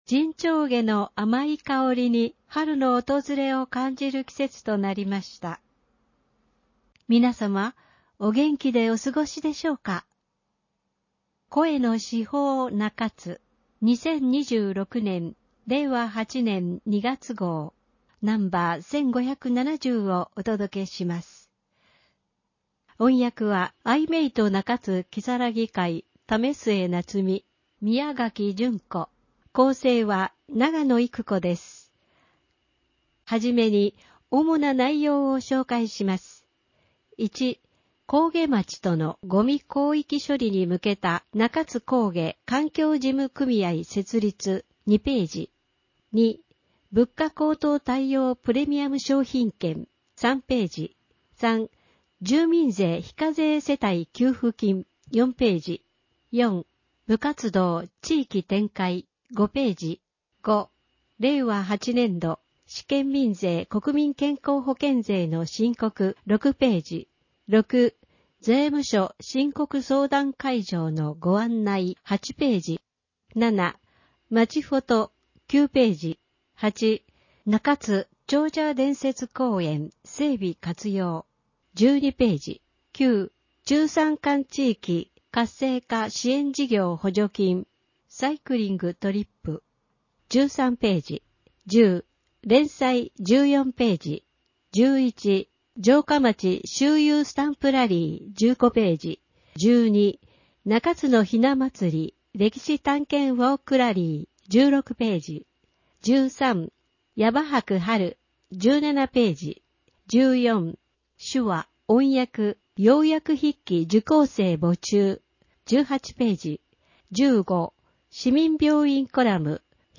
市報の内容を音声で聞くことができます。 アイメイト中津きさらぎ会がボランティアで製作しています。
声の市報 市報の内容を音声で聞くことができます。